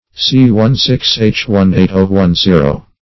fraxin \frax"in\, n. [From Fraxinus.] (Chem.)